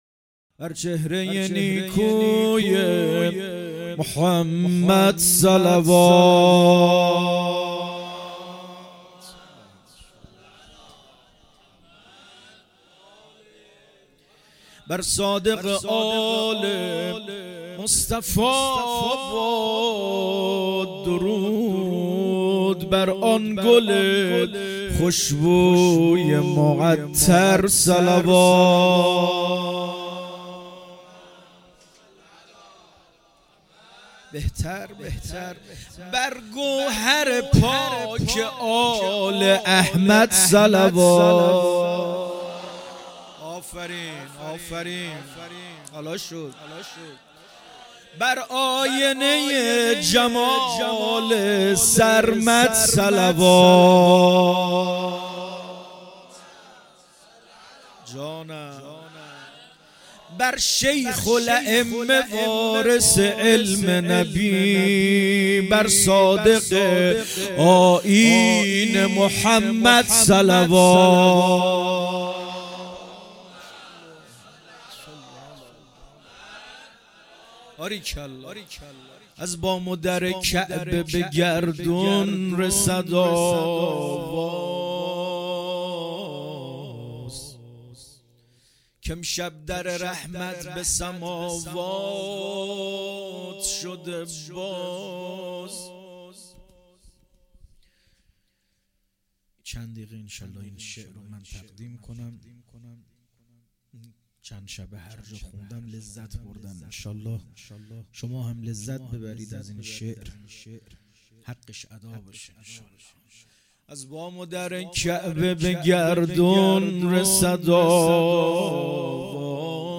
شعر خوانی